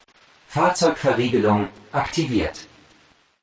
Tesla Lock Sound German Man
German male voice saying
(This is a lofi preview version. The downloadable version will be in full quality)
JM_Tesla-Lock_German_Man_Watermark.mp3